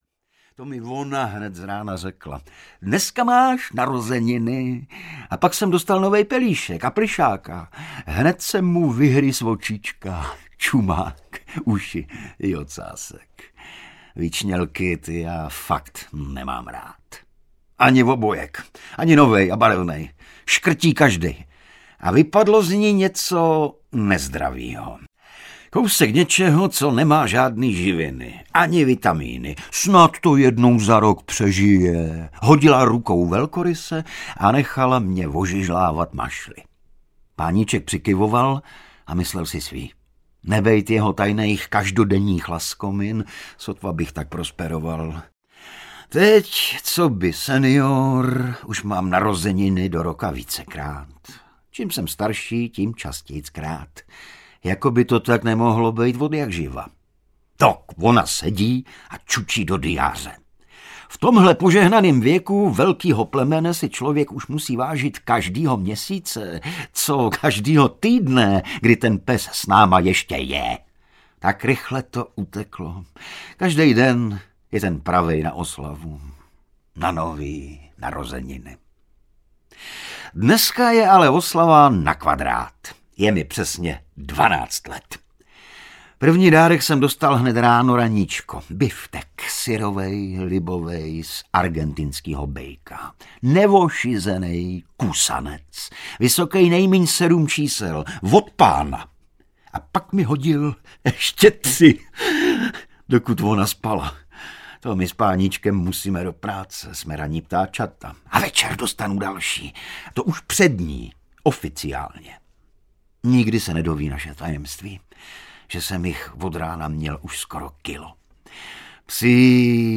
EGON: Děsná psina audiokniha
Ukázka z knihy
• InterpretIgor Bareš